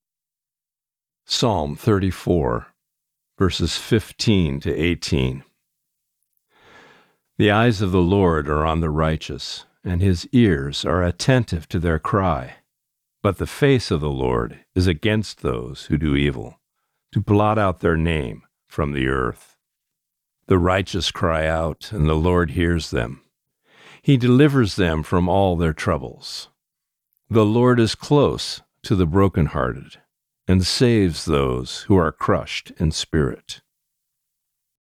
Today’s Reading: Psalm 34:15-18